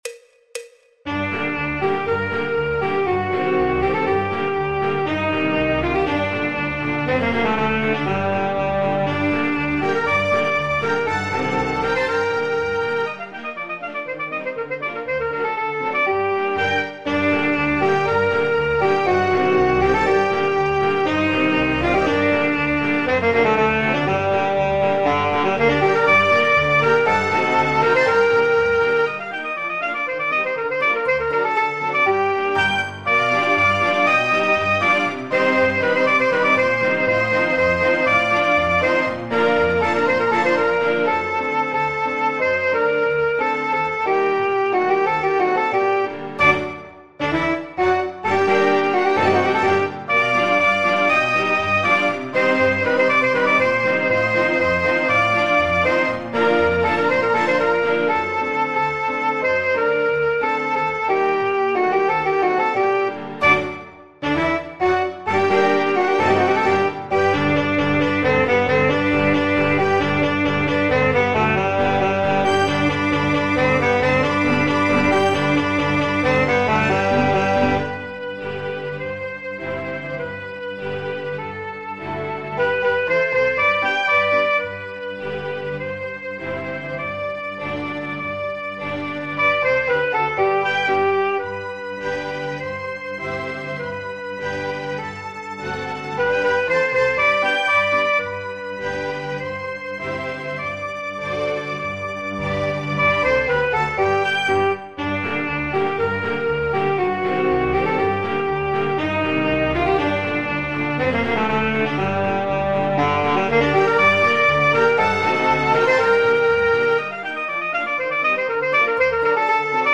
El MIDI tiene la base instrumental de acompañamiento.
Popular/Tradicional
Saxofón Alto / Saxo Barítono